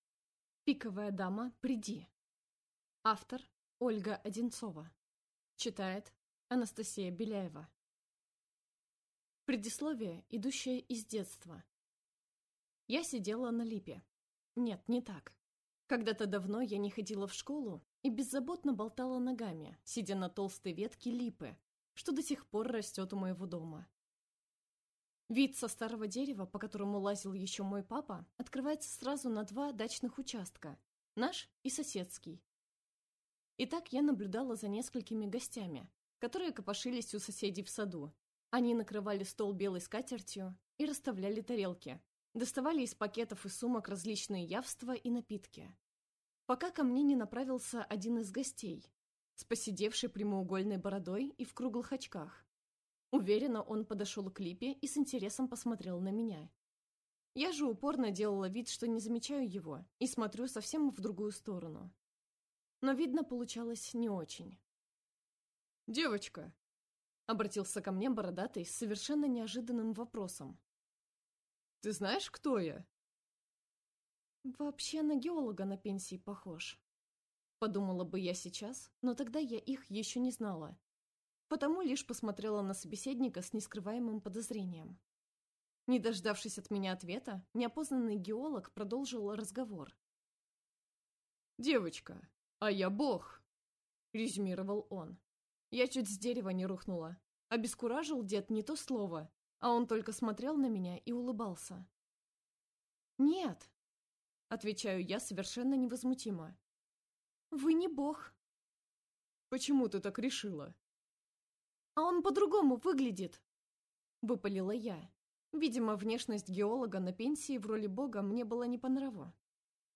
Аудиокнига Пиковая дама, приди!